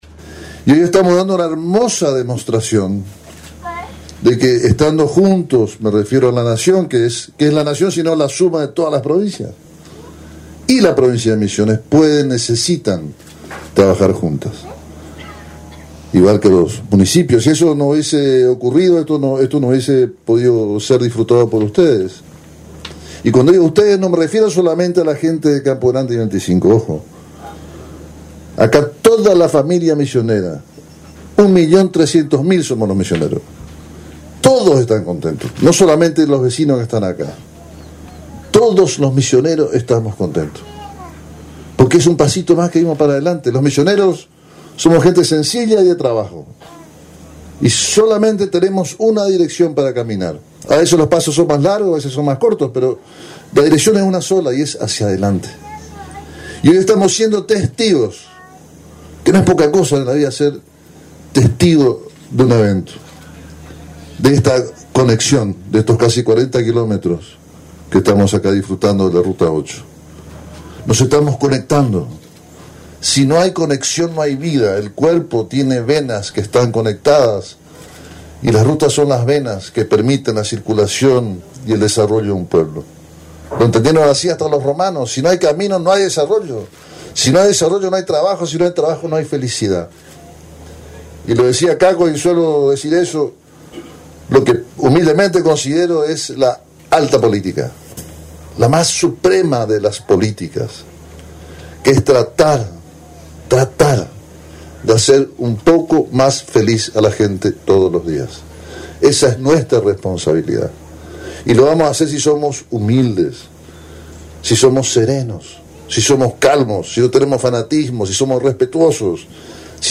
Audio-HP-Inauguración-Ruta-8-1.mp3